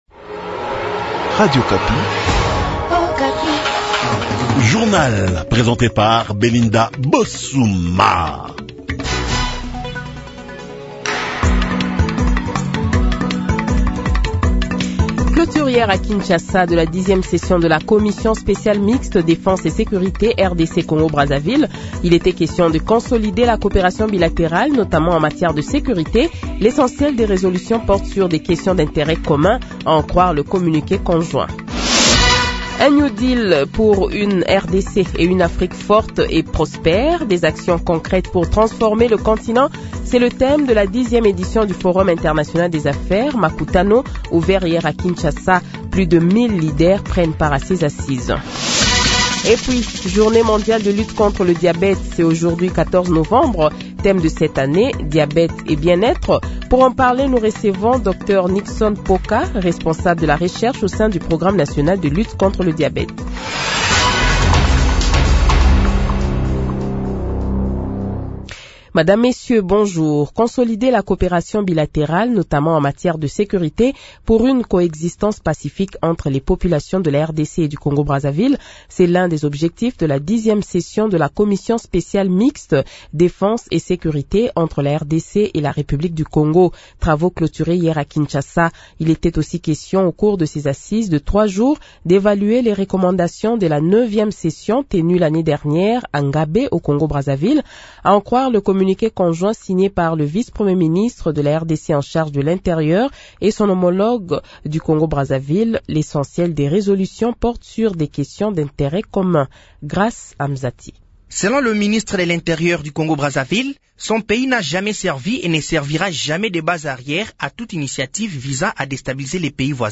Le Journal de 7h, 14 Novembre 2024 :